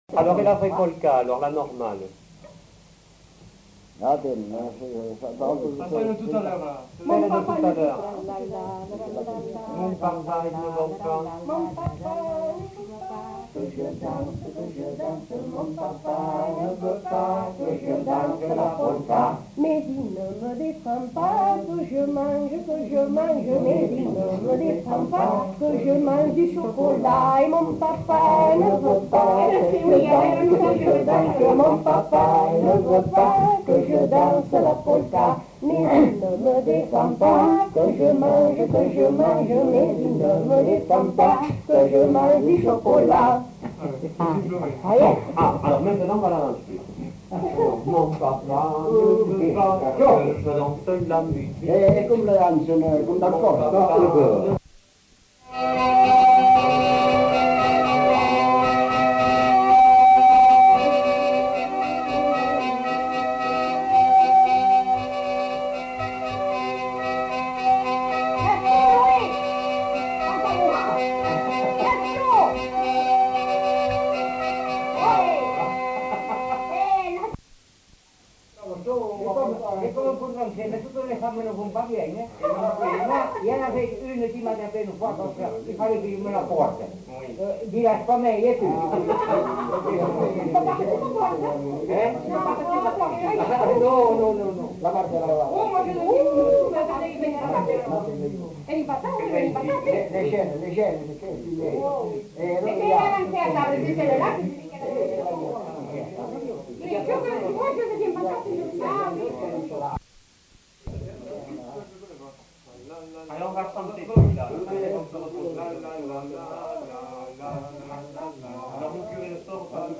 Aire culturelle : Grandes-Landes
Lieu : Luxey
Genre : chant
Type de voix : voix mixtes
Production du son : chanté
Danse : polka
Un seconde mélodie est fredonnée par les informateurs.